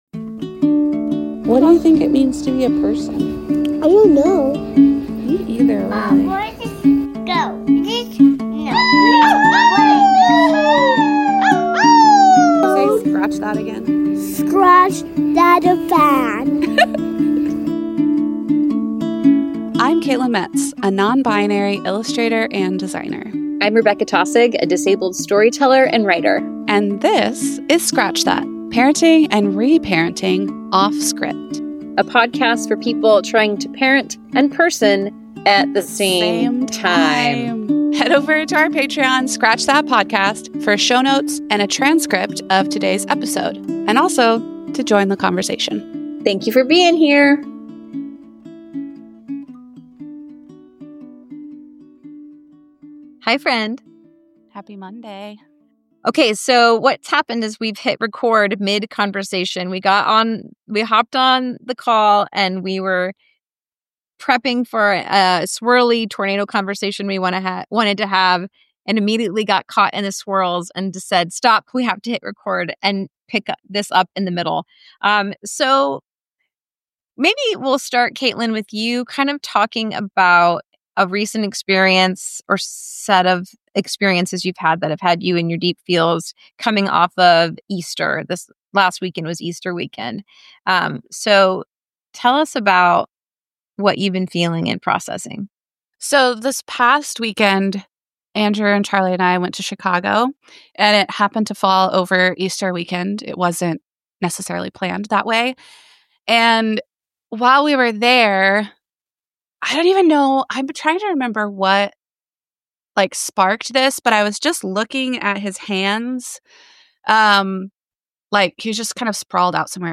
Conversations